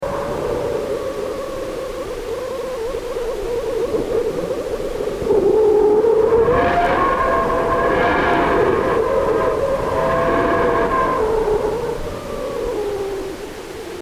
A wind of fast moving particles blows out from our Sun, and although space transmits sound poorly, particle impact and variable-field data from NASA's near-Sun Parker Solar Probe is being translated into sound.
psp_whistler_mode_waves_2.mp3